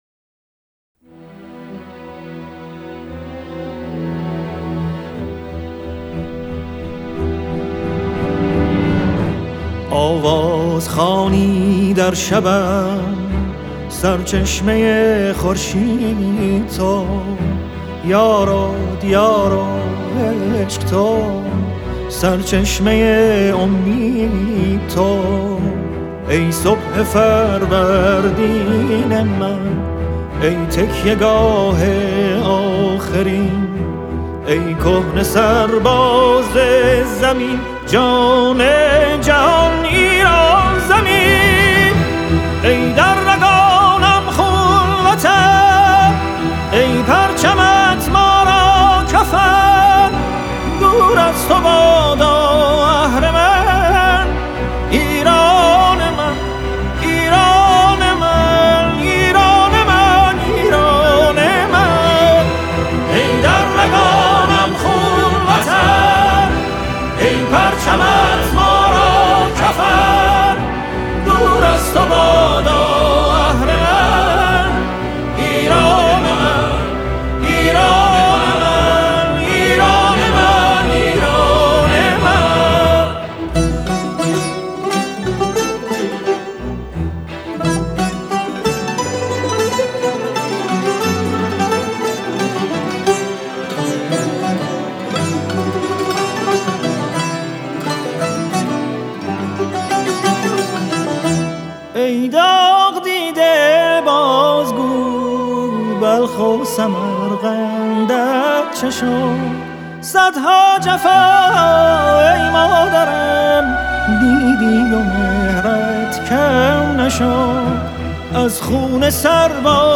در این فهرست، تعدادی از موسیقی‌های حماسی باکلام